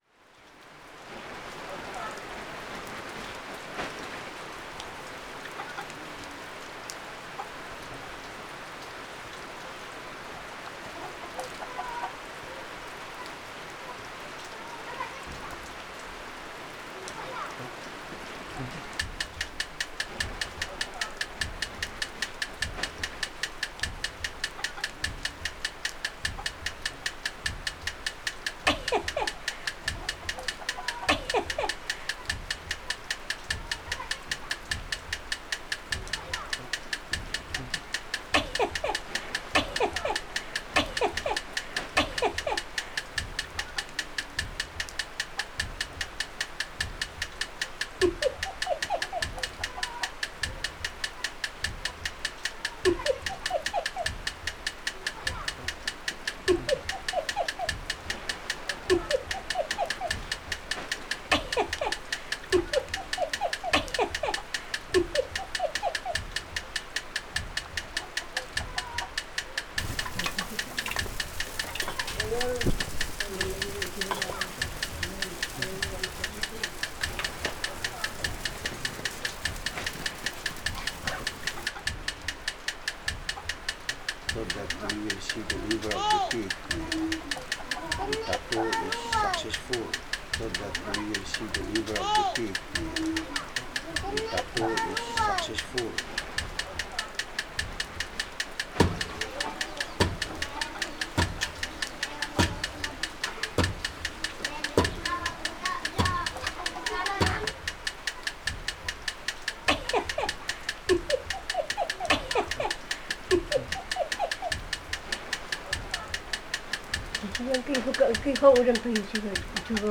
sound installation constructed rhythmically, harmonically, and melodically from high-density stereo field recordings